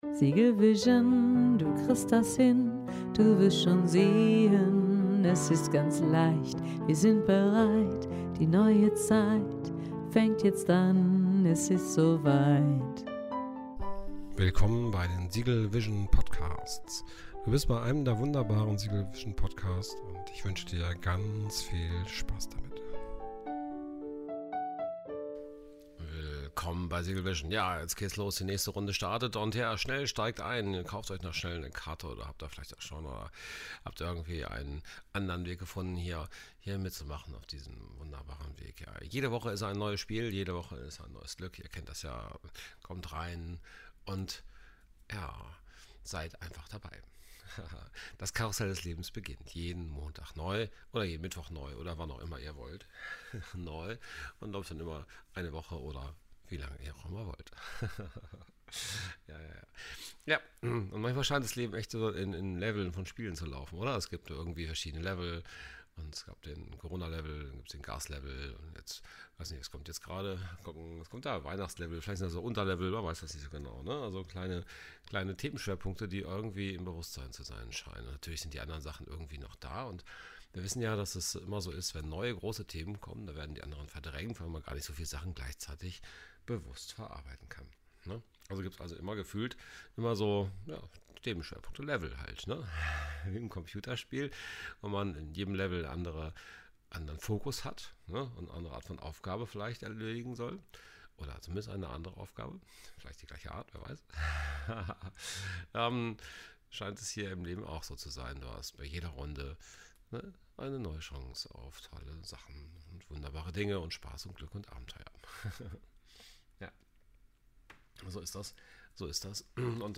Neue Runde im Karussell des Lebens Themenschwerpunkte Spiellevel Poetry Jazz Poetry mit spontanem Jazz Wohnzimmerfeeling Inspiration